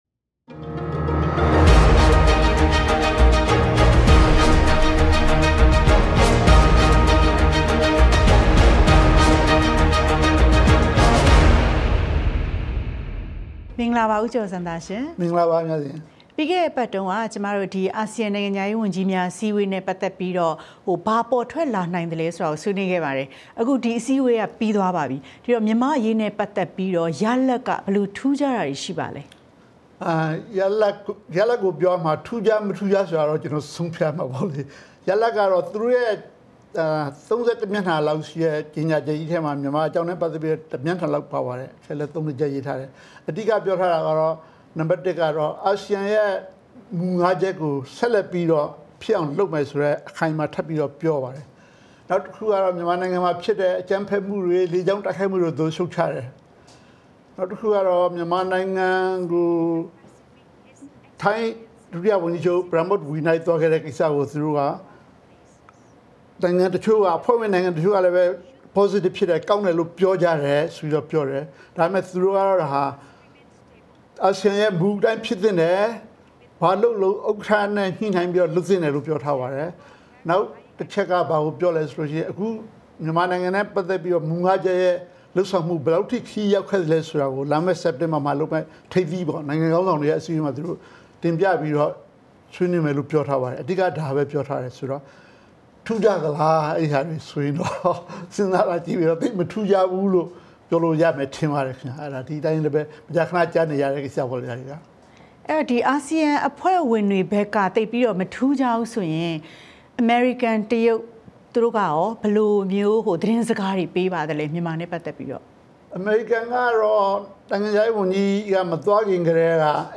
မေးမြန်းတင်ပြထားပါတယ်။